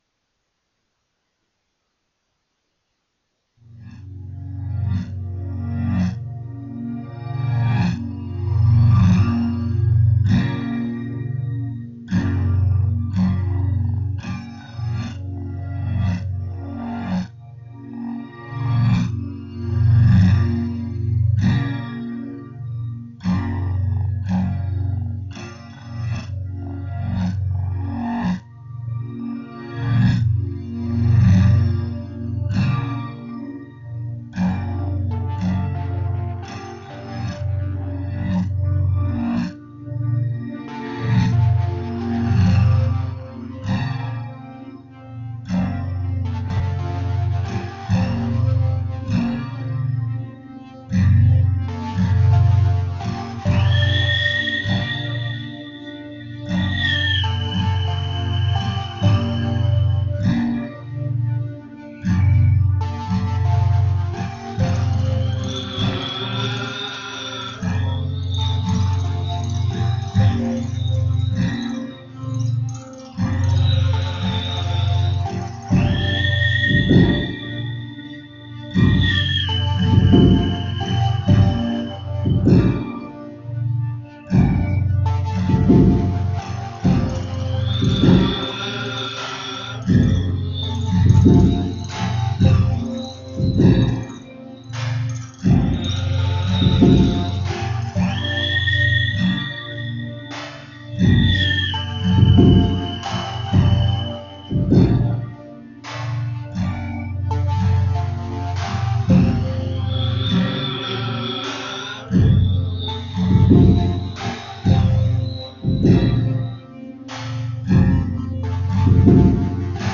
A Study in voice pitch and melody.